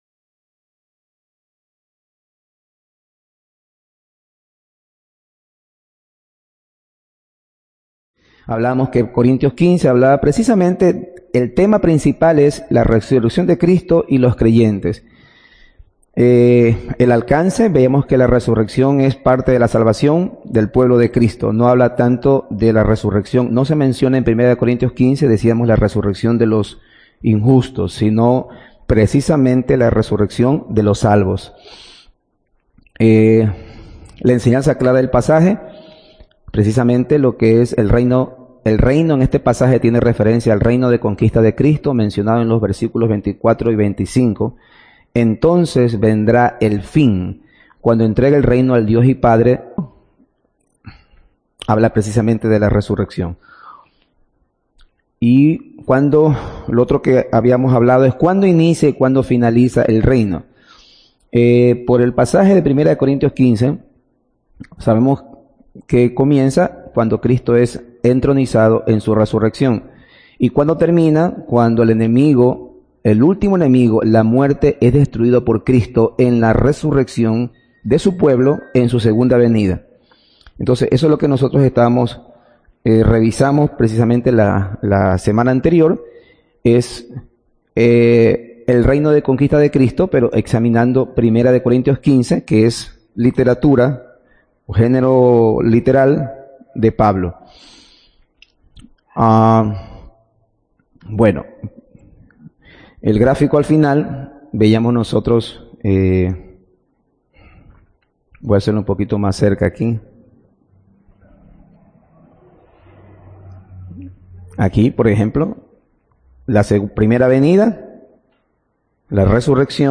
ESTUDIO BÍBLICO DE ESCATOLOGIA - CONFESION BAUTISTA DE FE DE 1689 - DOCTRINA DE LAS ULTIMAS COSAS EL MILENIO
Audio del sermón